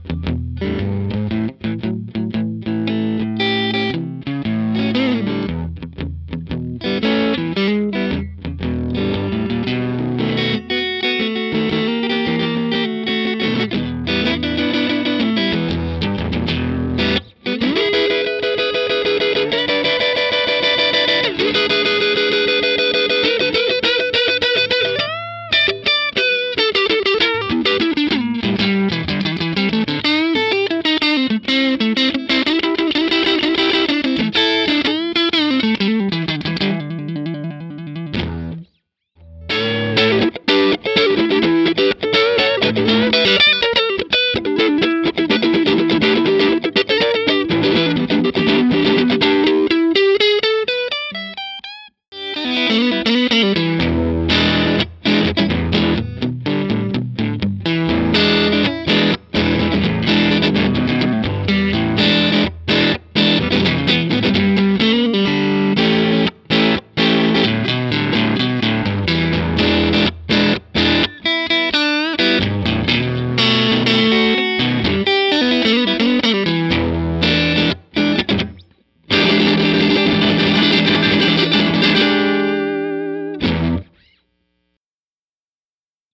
Totally unstructured, free form clips!
With the JTM I used my TTA Trinity cab, the Marshall with Greenbacks, and the Mesa Recto with V-30s.
I set up 2 mics this time – a Shure SM57 and an Audix i5. The i5 has a more pronounced top end and bigger bottom and compliments the 57’s midrange thing really well.
B_JTM2_Input1Lo_Strat_Neck_TTA.mp3